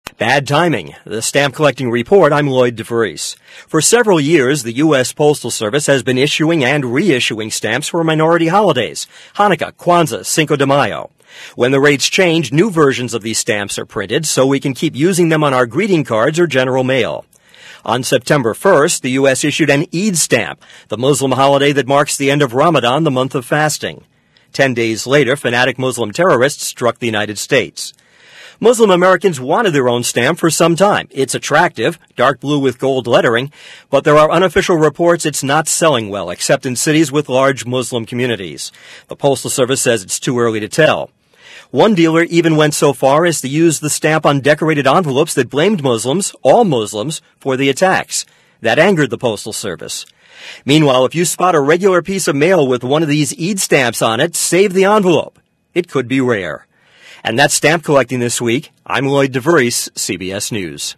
For broadcast on CBS Radio Network stations